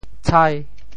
How to say the words 豺 in Teochew？
tshai5.mp3